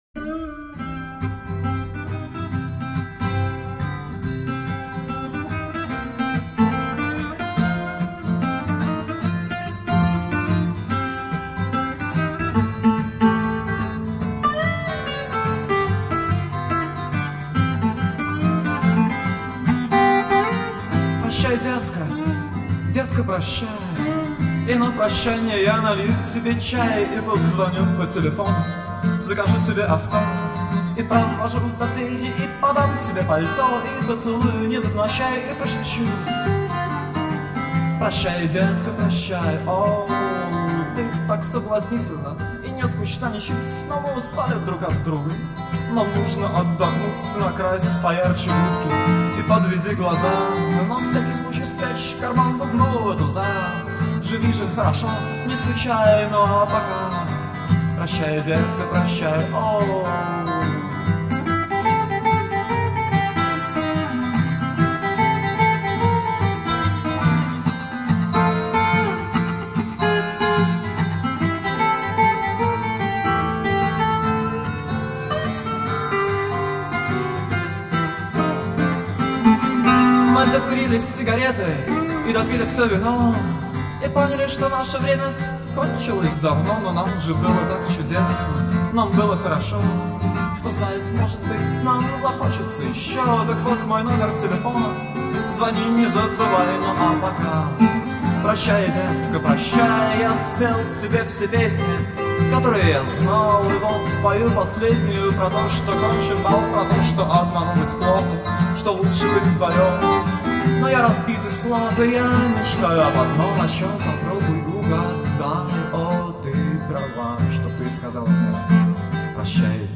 губная гармошка, голос.